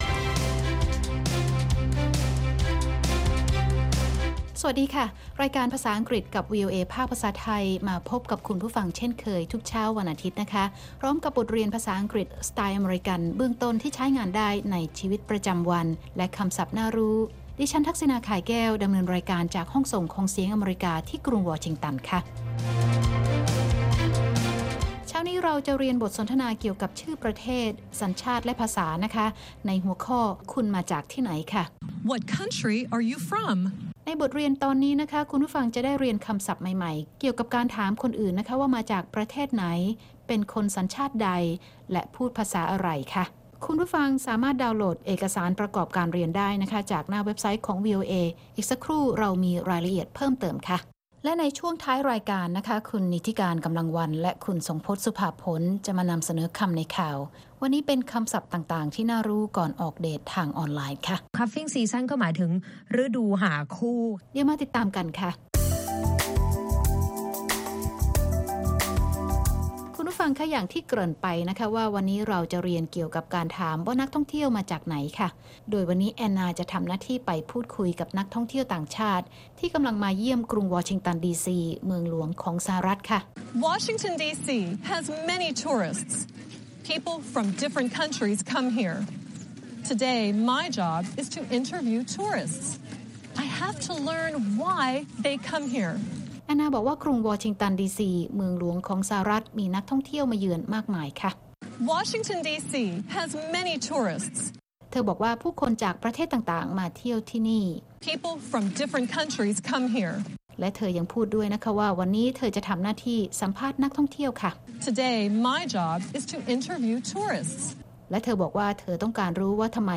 ภาษาอังกฤษกับ VOA Thai สอนภาษาอังกฤษด้วยสำนวนที่คนอเมริกันใช้ มีตัวอย่างการใช้ และการออกเสียงจากผู้ใช้ภาษาโดยตรง